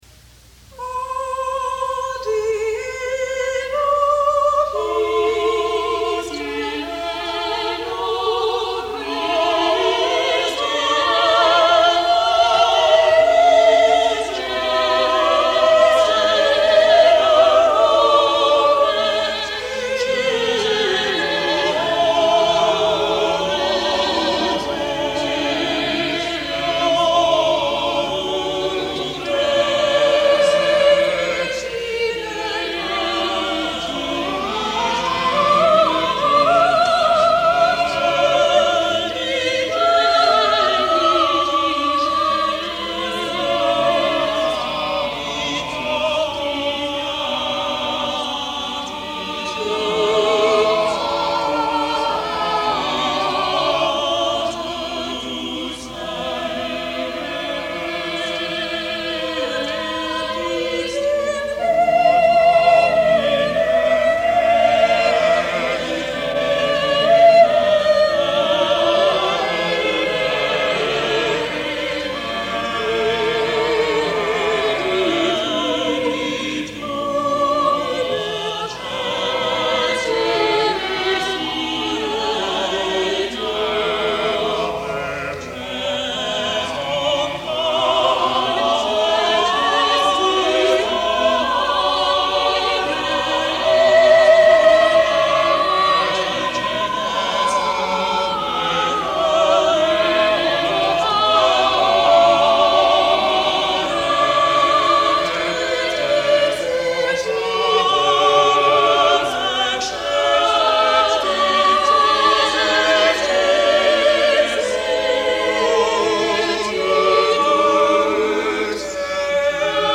| Vocal Ensemble 'Nowell' 1984